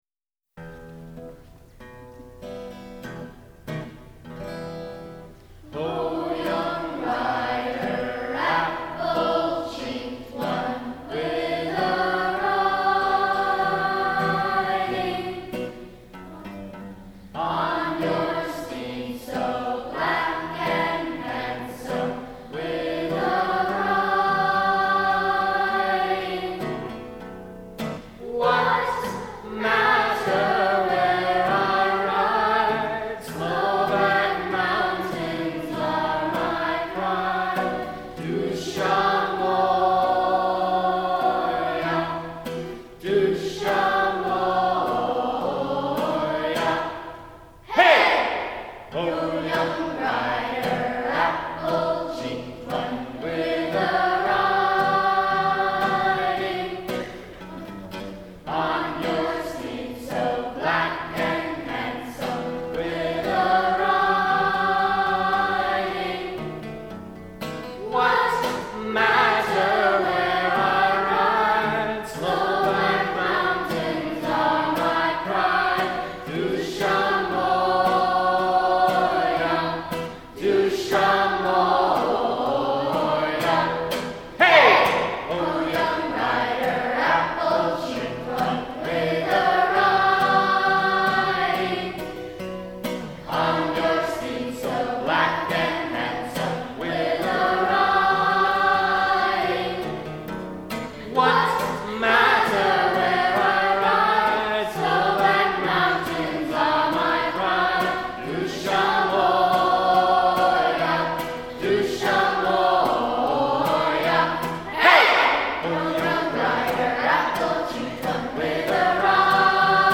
It uses the entire melodic minor scale and mixed meter (4 + 5) to create an exotic, Eastern European atmosphere.
& guitar
Linden Corner School was a small private K-6 school in 2005, and the unauditioned chorus consisted of every student in grades 4, 5, & 6.
The allure and probably defining characteristic of this song is its use of cumulative accelerando.
On the final, unpitched, shouted Hey! at the end of each verse, it is traditional to make a fist with your right hand and raise it in the air, with emphasis, on the beat.